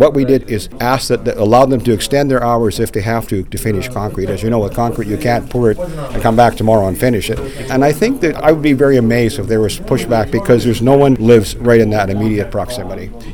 Here’s the mayor with a little bit more on the project.